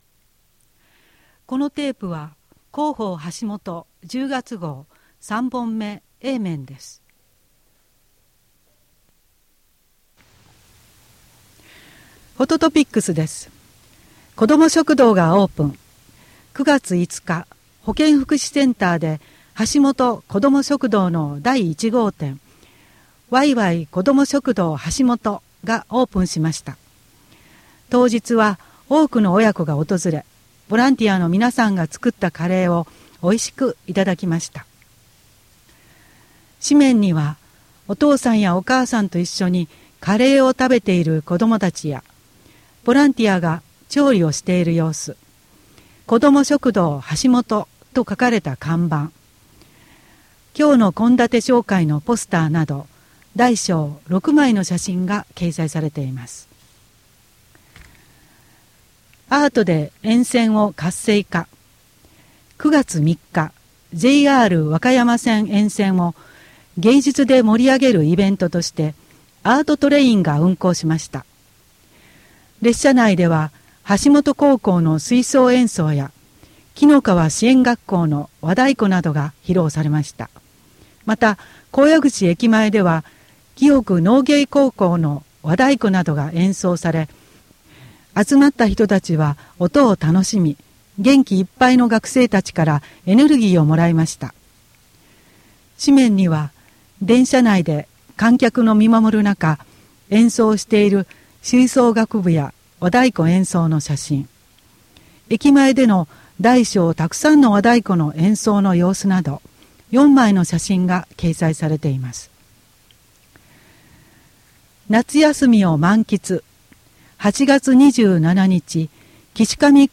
WEB版　声の広報 2017年10月号